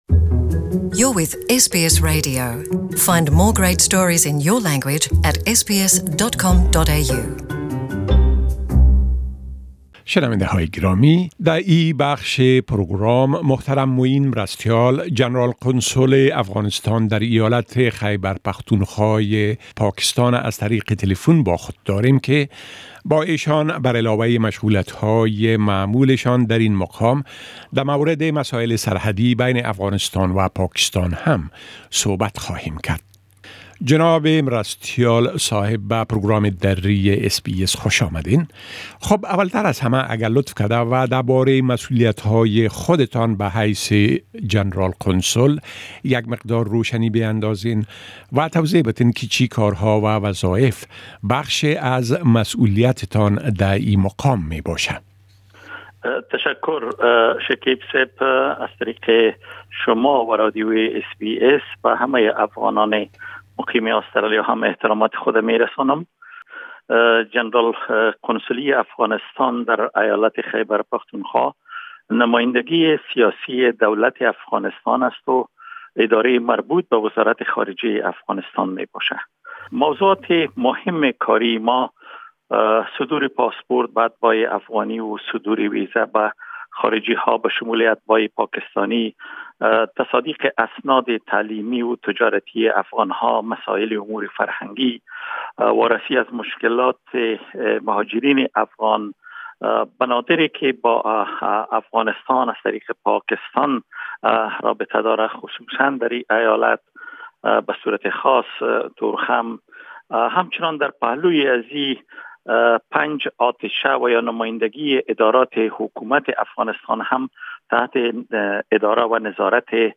Interview with Afghan consul general in Peshawar about Afghan Pakistan relations